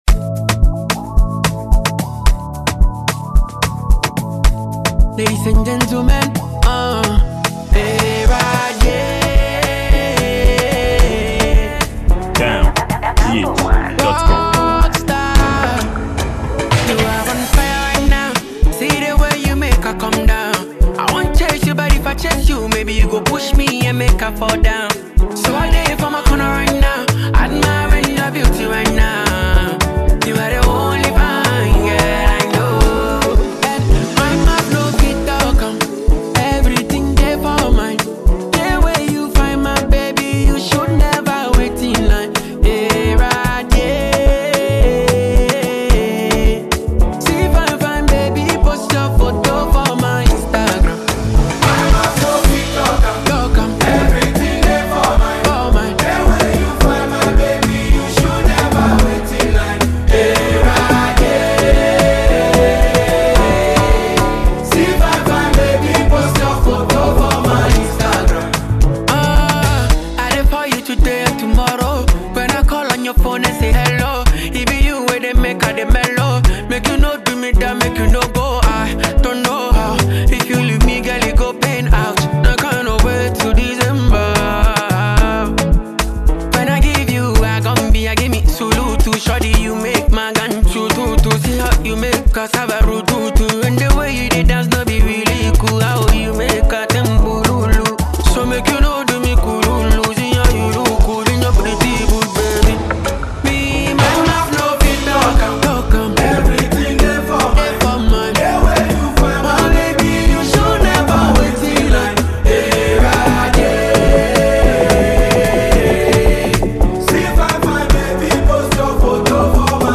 Genre: Afrobeat/Highlife